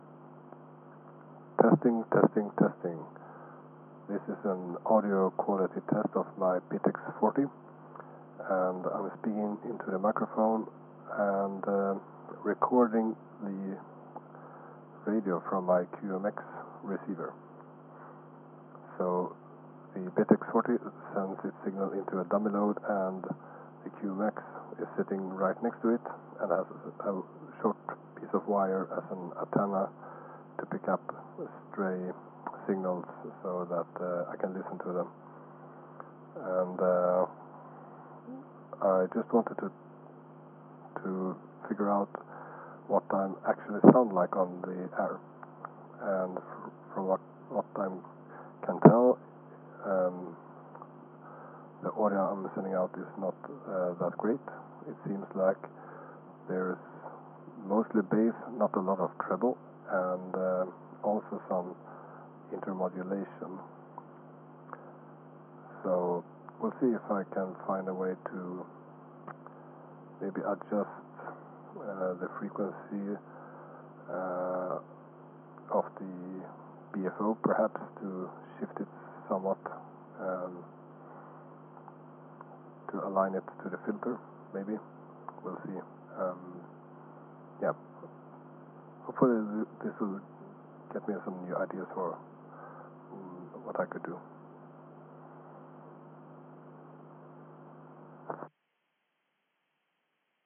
Tried a thing that has been on my to-do list for ages: listening to the signal that the BITX40 puts out! Here I transmitted into a dummy load and let the QMX pick up the RF using a small wire.
bitx40_audio_test.mp3